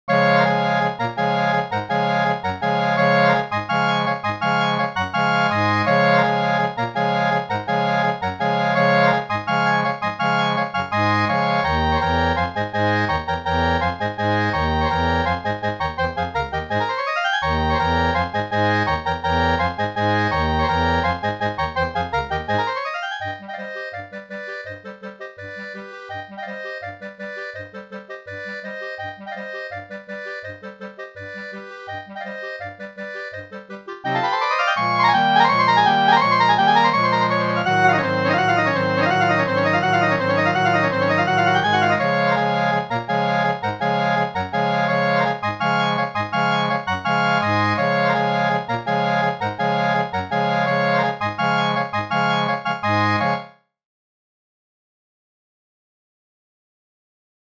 It is a real showpiece for Eb, and Bb clarinets.